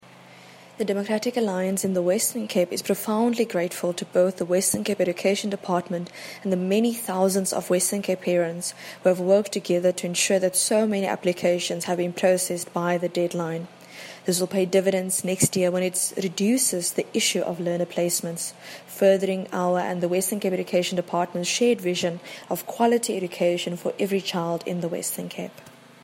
English soundbite of MPP Deidré Baartman attached.